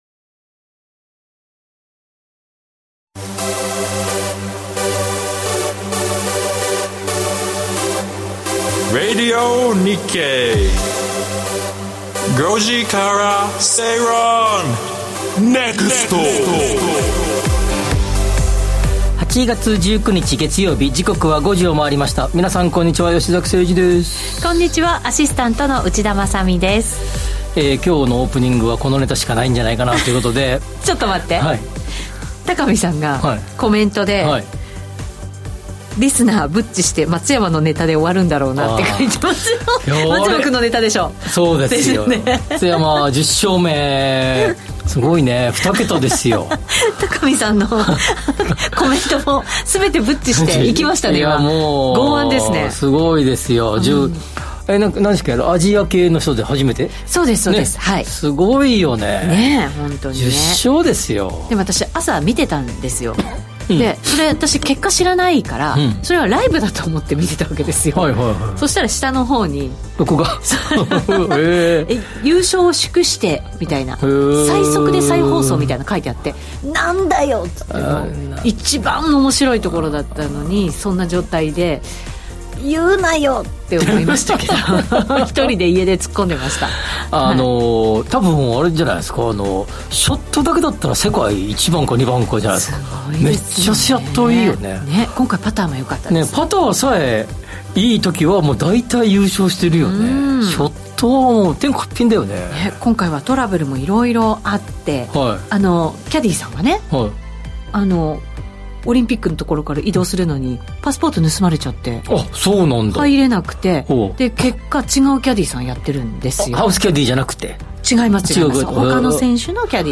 ビジネスマンや個人投資家に向けて 「景気・経済動向をわかりやすく解説する月曜日」 明日の仕事でつかえるネタ、今夜の食事時に話したいネタを、人生を豊かにするネタをざっくばらんな雰囲気でお届けします！